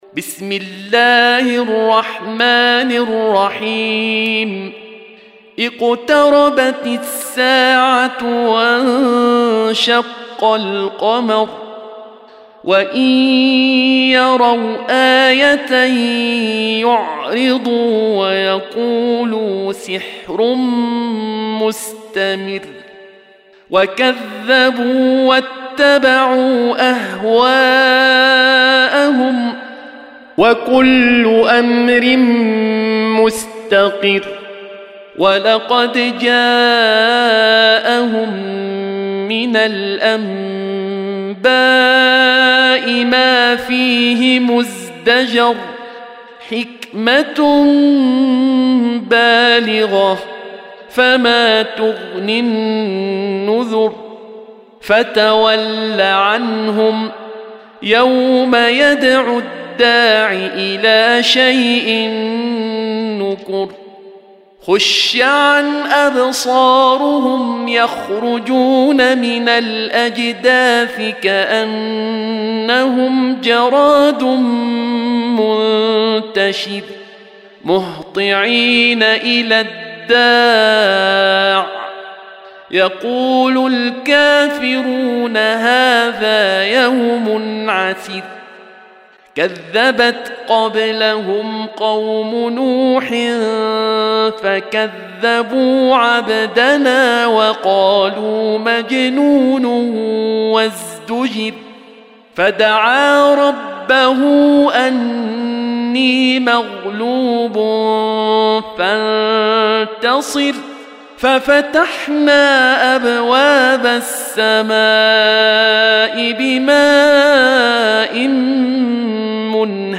Surah Sequence تتابع السورة Download Surah حمّل السورة Reciting Murattalah Audio for 54. Surah Al-Qamar سورة القمر N.B *Surah Includes Al-Basmalah Reciters Sequents تتابع التلاوات Reciters Repeats تكرار التلاوات